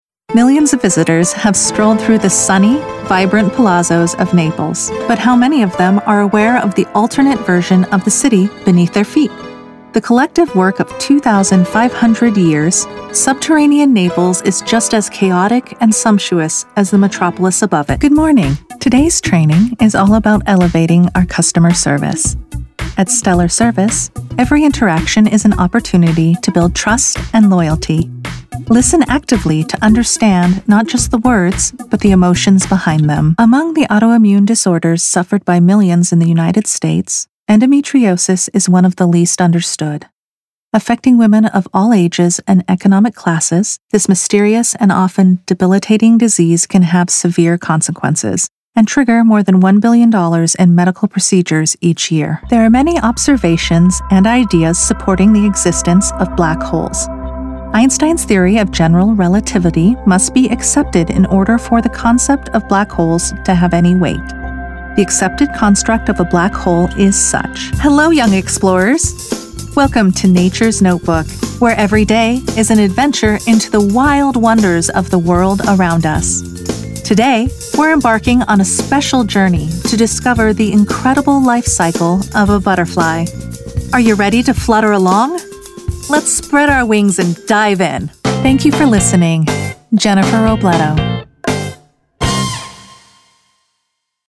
Narrative demo
Young Adult
Middle Aged